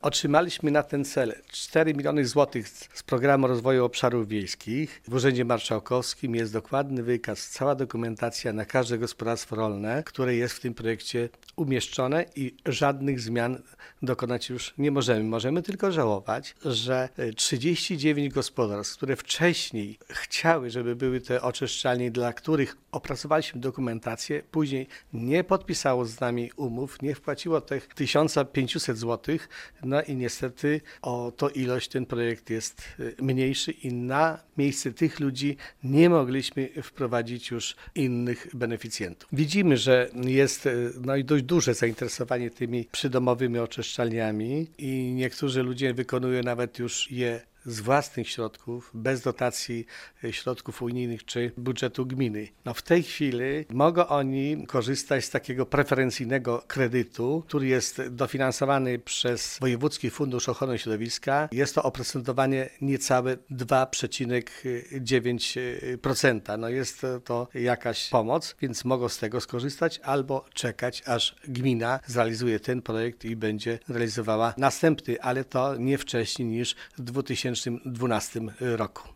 Wiktor Osik przyznaje, że zainteresowanie przydomowymi oczyszczalniami jest duże.